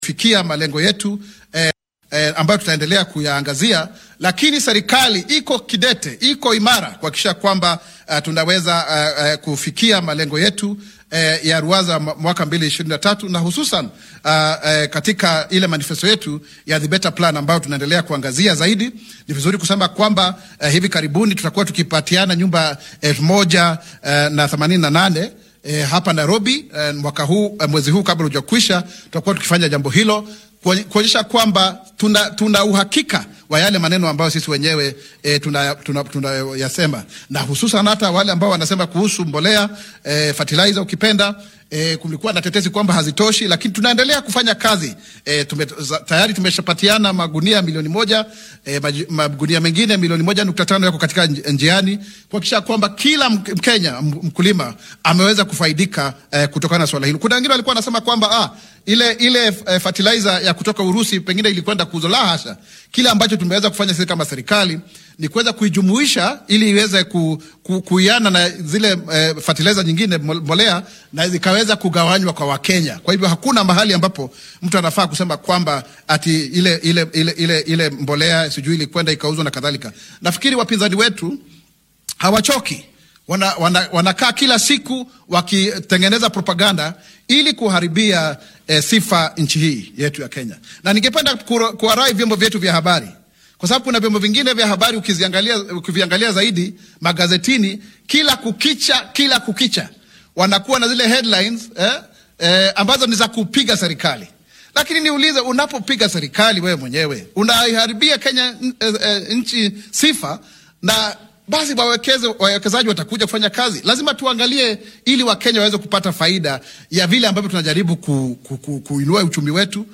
Waxaa uu intaas ku darey in 1.5 milyan oo bacrimin ah la siina doona beeralayda . Arrintan ayuu shaaca ka qaadey xil uu shir jaraa’Id ah qabtay.